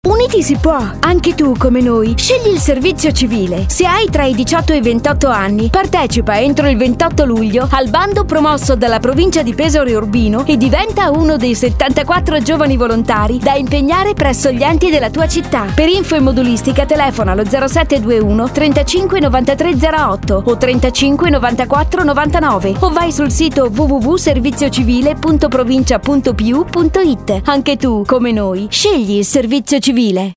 Spot radiofonico